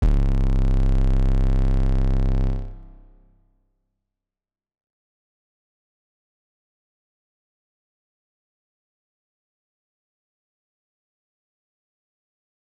808 (way back).wav